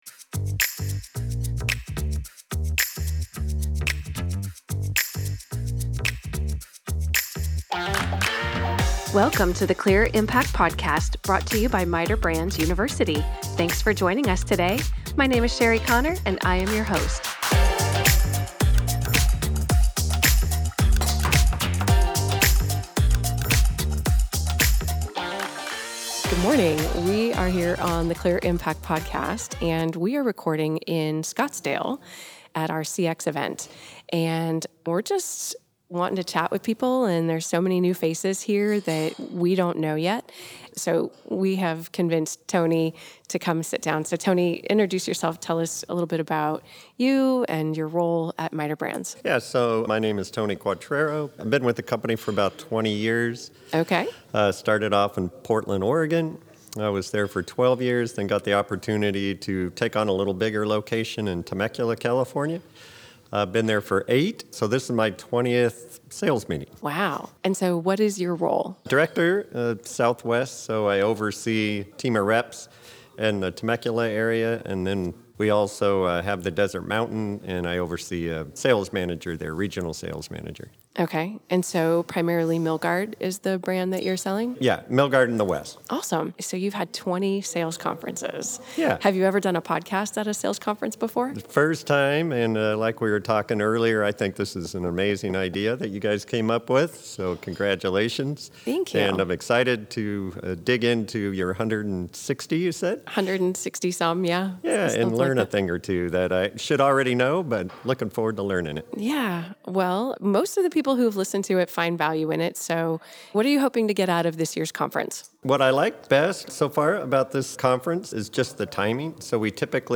With over 300 people in attendance from across the country, we were able to have several conversations with folks experiencing this inspirational event.